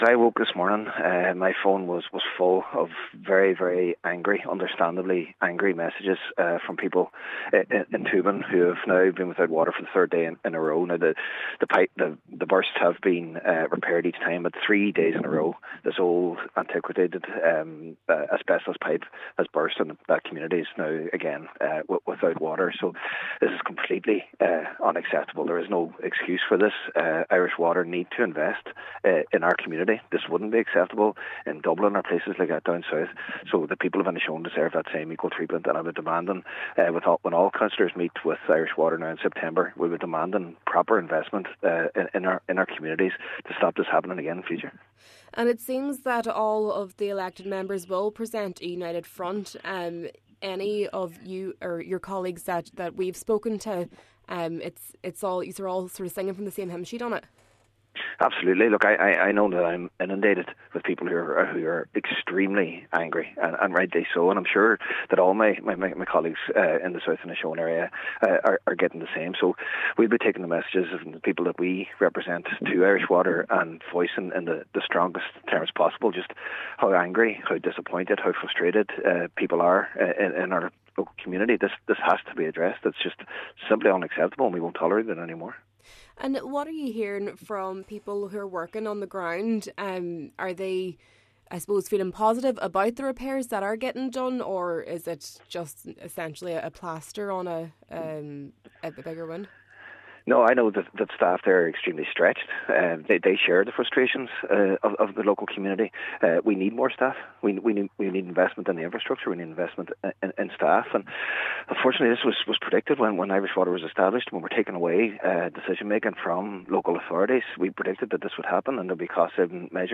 Cllr Jack Murrays says they will present a united front: